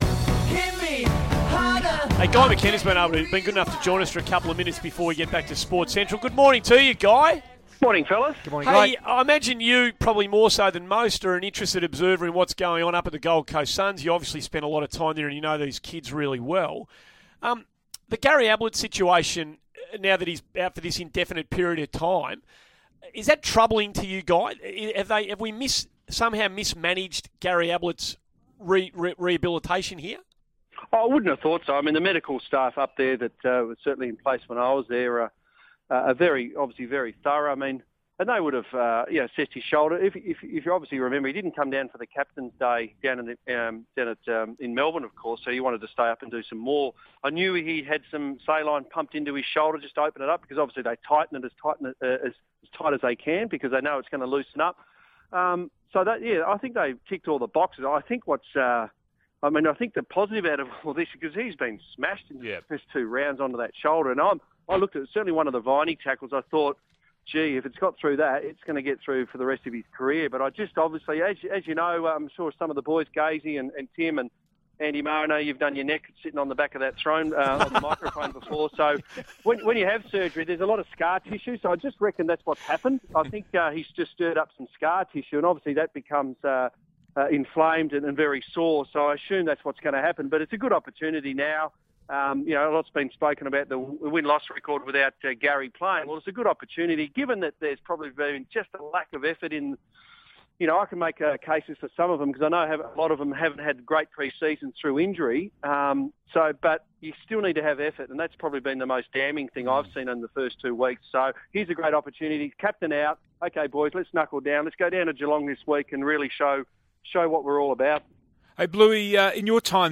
Former Gold Coast Suns coach Guy McKenna joined Tim Watson, Andy Maher and Andrew Gaze to chat about Gary Ablett and his shoulder injury.